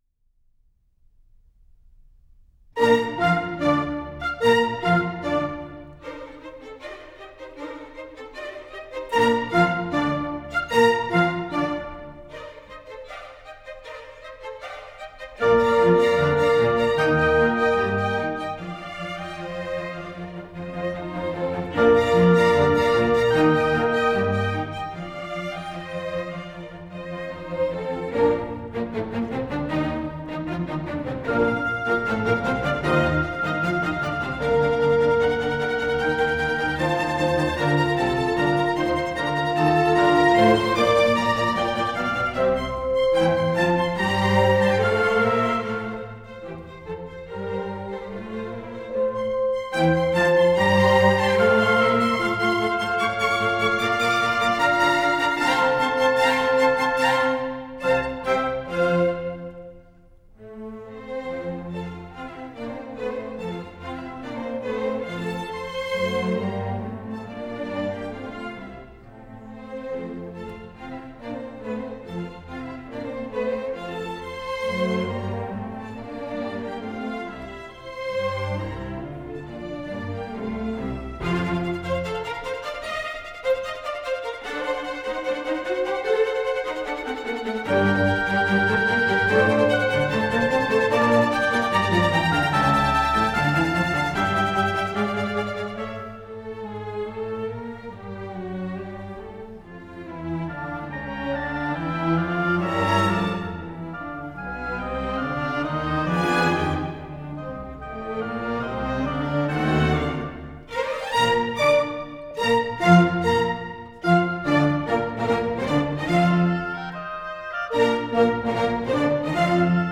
Allegro spiritoso